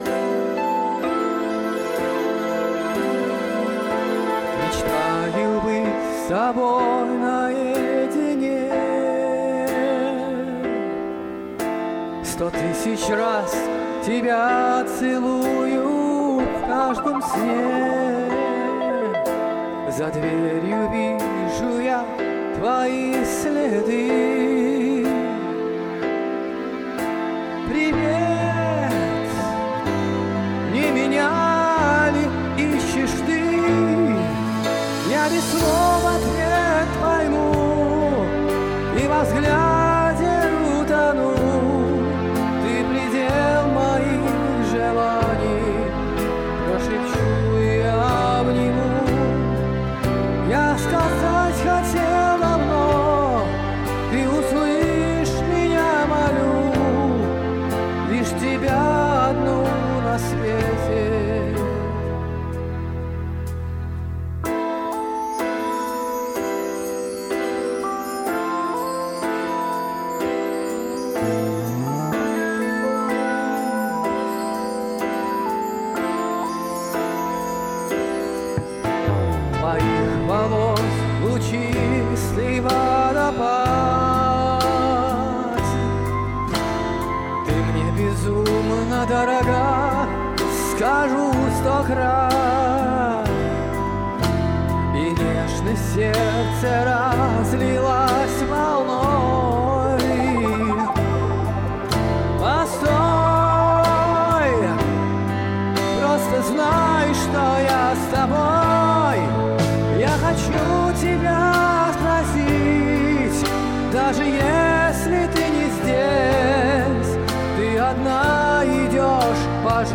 🎶🔉 КАВЕР